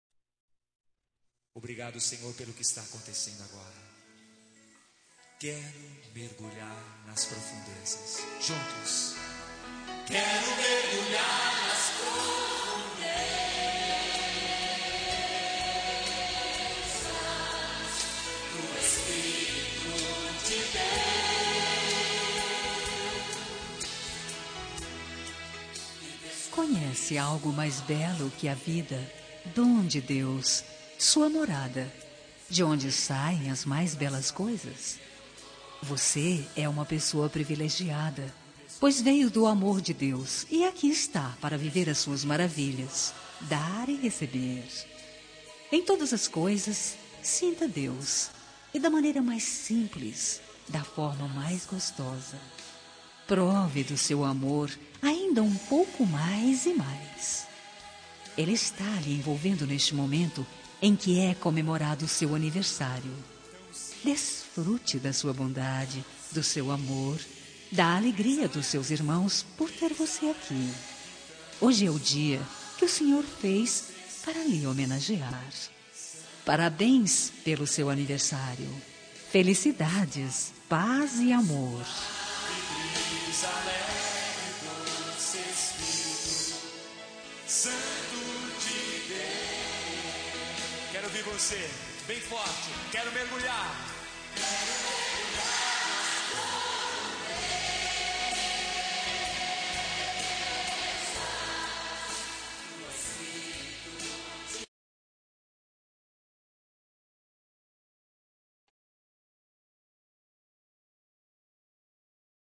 Telemensagem Aniversário de Mãe – Voz Feminina – Cód: 1425 Religiosa